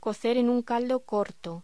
Locución: Cocer en un caldo corto
voz